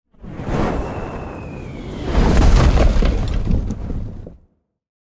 meteor_fall_hit.ogg